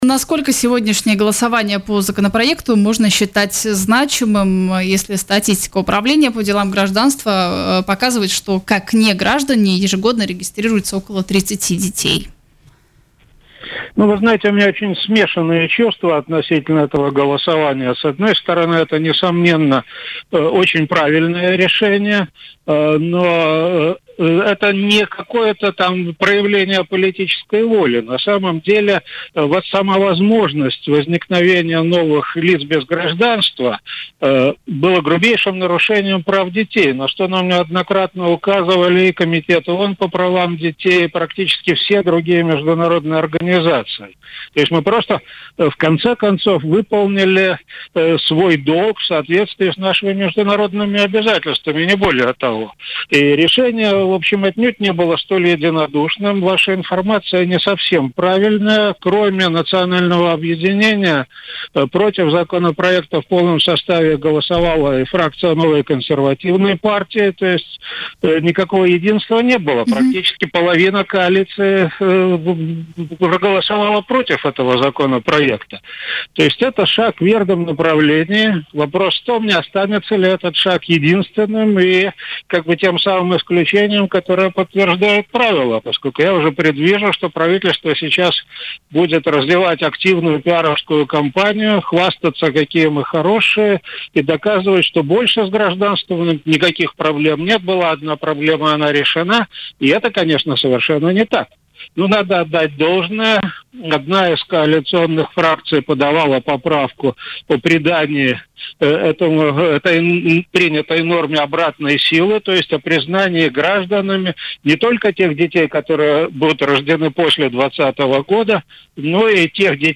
Решение Сейма утвердить автоматическое присвоение гражданства Латвии детям неграждан во многом обусловлено давлением со стороны международных организаций, а не политической волей латвийской власти. Об этом в эфире радио Baltkom заявил депутат Сейма от партии «Согласие», зампредседателя Комиссии по общественным делам и правам человека Борис Цилевич.